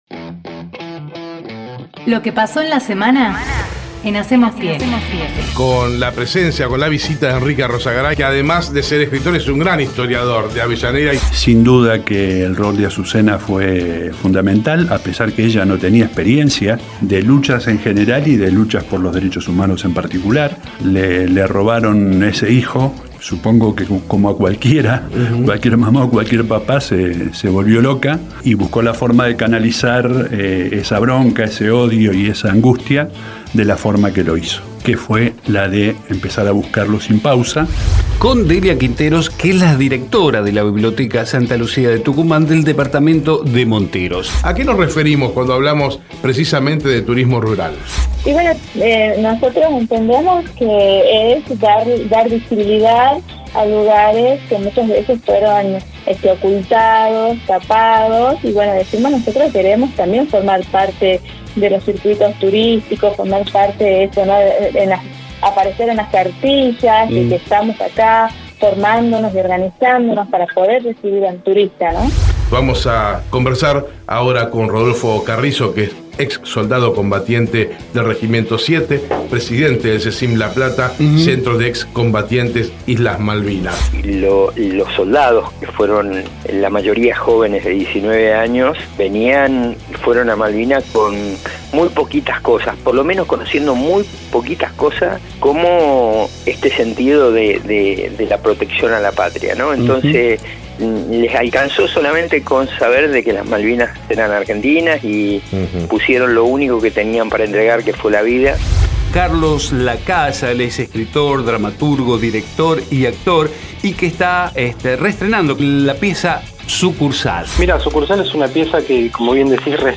La semana de Hacemos PyE Texto de la nota: Escuchá todo lo que pasó en La semana de Hacemos PyE. Compartimos el resumen semanal del 28 de marzo al 1 de abril. Nuestras entrevistas con agenda propia.